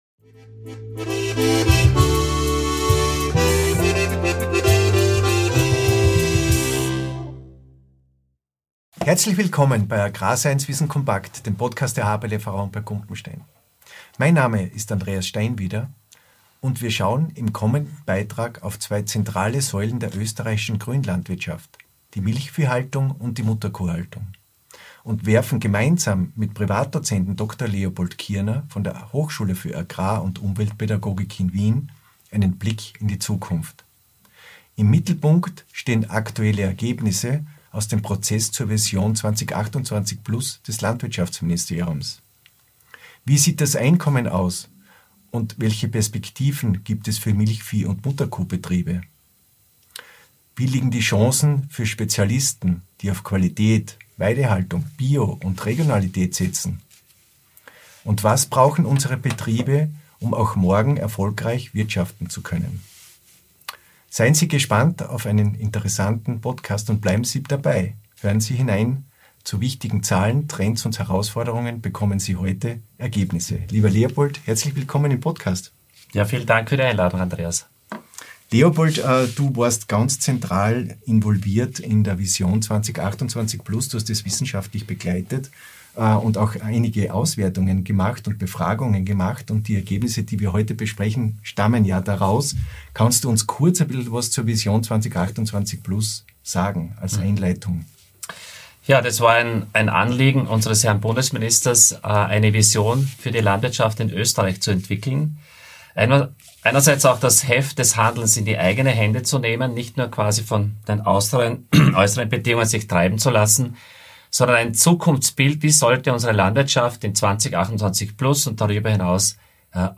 Im Mittelpunkt stehen zwei zentrale Bereiche der österreichischen Landwirtschaft: die Milchviehhaltung und die Mutterkuhhaltung. Wir beleuchten, wie sich Einkommen, Betriebsstrukturen und Zukunftserwartungen verändern, warum viele Landwirt:innen Planungssicherheit fordern und wo die Chancen für spezialisierte Betriebe liegen. Ein Gespräch über Herausforderungen – und über Betriebe, die mit Qualität, Weidehaltung und Innovationsgeist neue Wege gehen.